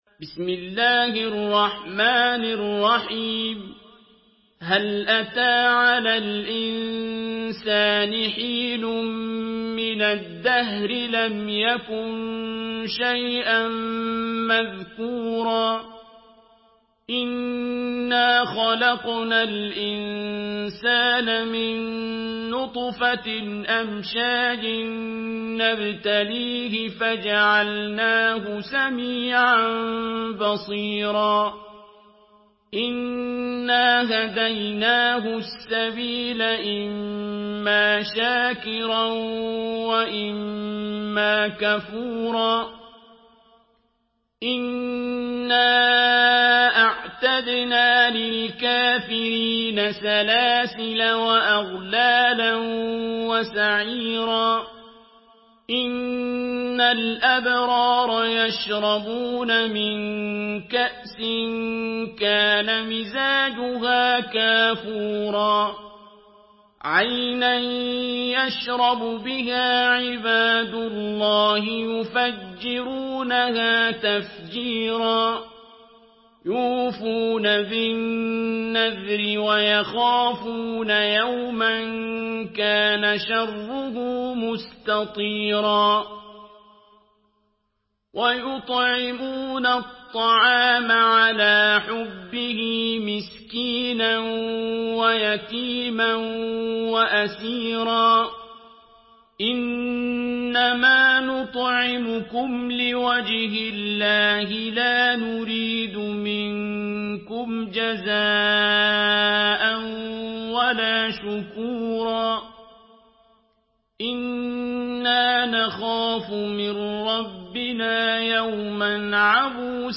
Surah الإنسان MP3 by عبد الباسط عبد الصمد in حفص عن عاصم narration.
مرتل